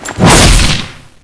Index of /server/sound/weapons/tfa_cso/dreadnova
stab.wav